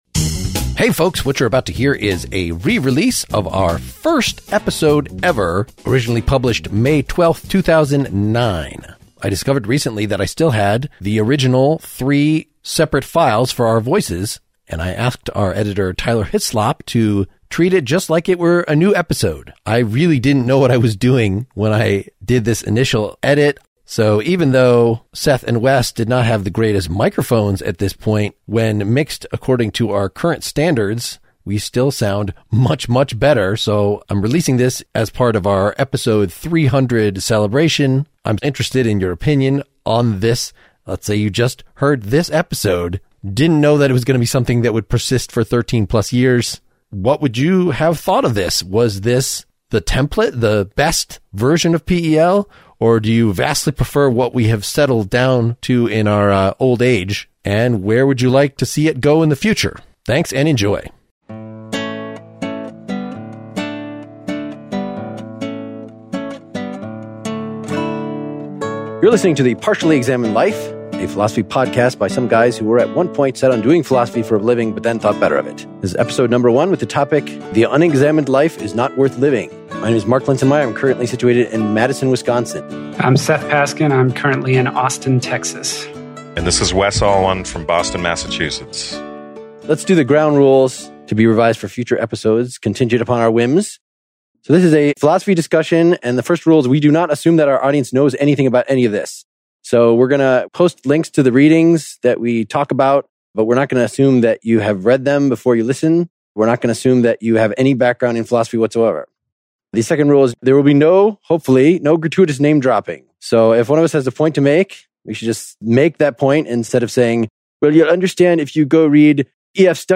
from the original voice files of our very first discussion, covering Plato's "Apology."